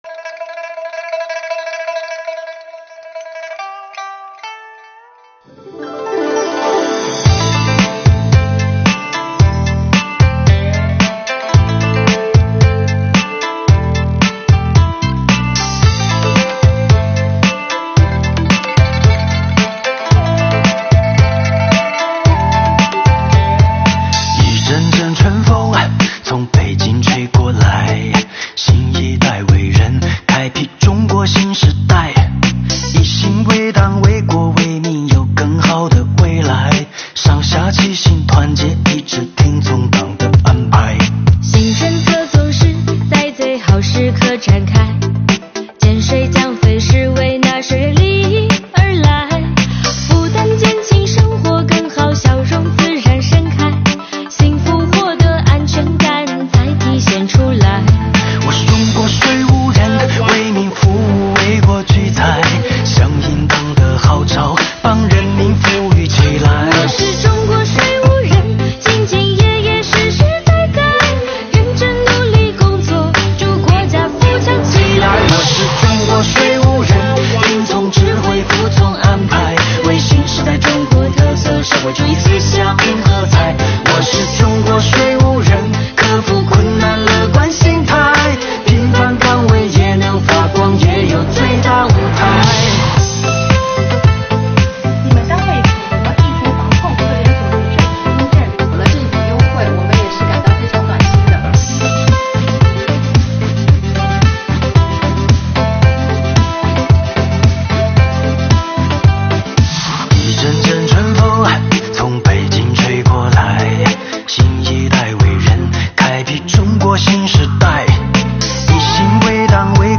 2022年新年伊始，我们用税务人录制的歌曲，为大家制作了一本音乐挂历，并将全年办税时间做了标注，伴着歌声开启新征程吧。